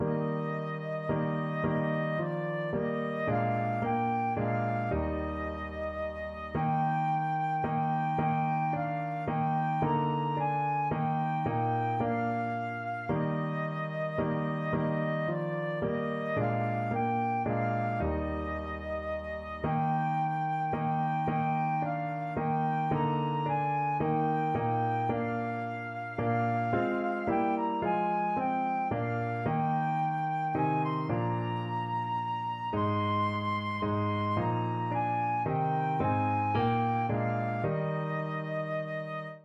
Christian Christian Flute Sheet Music Holy God, We Praise Thy Name
Flute
3/4 (View more 3/4 Music)
Eb major (Sounding Pitch) (View more Eb major Music for Flute )
Traditional (View more Traditional Flute Music)